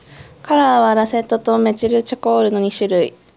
Nexdata/Japanese_Speech_Data_by_Mobile_Phone_Reading at fd05d932469de078d2a708af23e7037ca41fc2fe